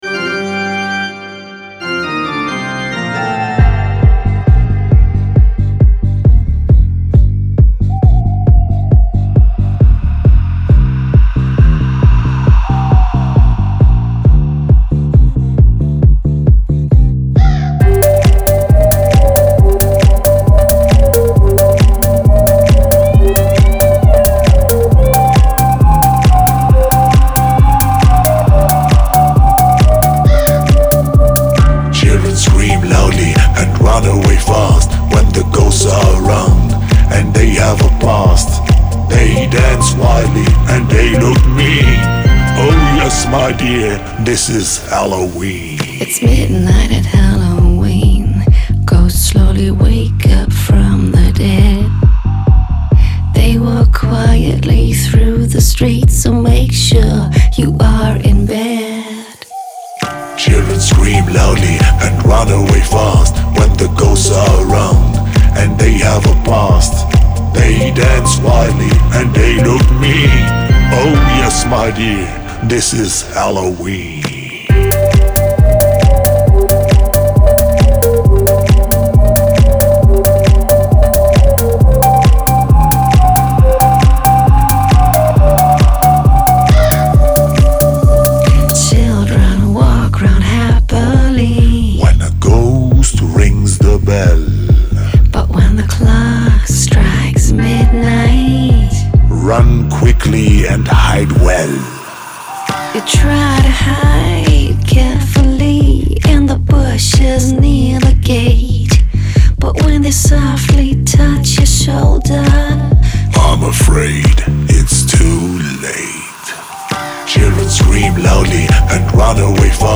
und das Ganze wiederum mit „fetziger“ Musik